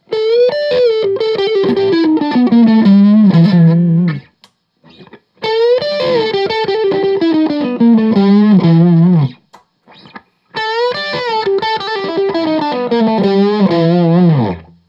12th Fret Riff
As usual, for these recordings I used my normal Axe-FX Ultra setup through the QSC K12 speaker recorded into my trusty Olympus LS-10.
For each recording I cycle through the neck pickup, both pickups, and finally the bridge pickup.
A guitar like this is really about that semi-hollow sound, and it delivers that in every position and on every fret, though that sort of hollow timbre can obviously get lost when the gain is up high and the effects are set to overwhelming.
If you listen carefully to the 12th Fret Riff in the JCM-800 section you can hear a bit of a flubb on the first iteration.